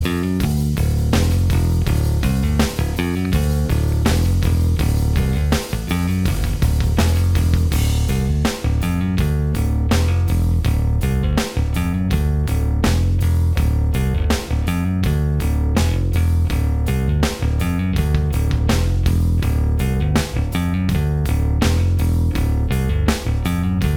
Minus Lead Guitar Rock 5:15 Buy £1.50